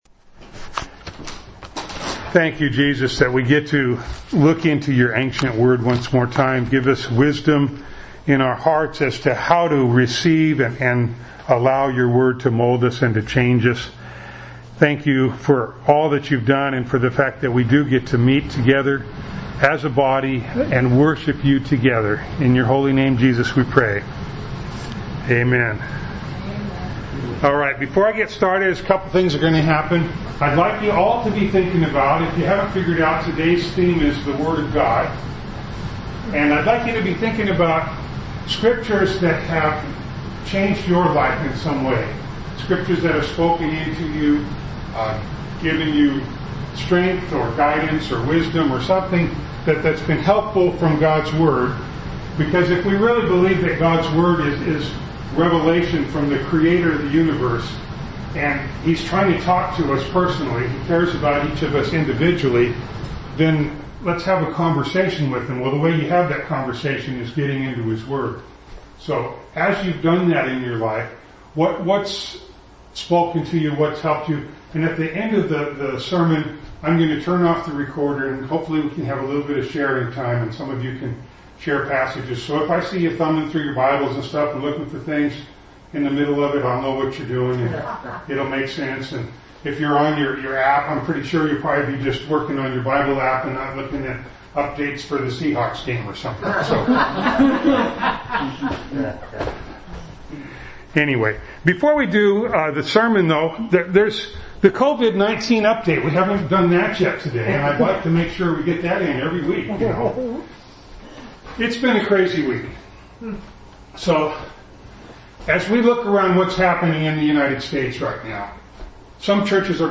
Ephesians 5:26b Service Type: Sunday Morning Download Files Notes « Love Like Christ Loved Radiant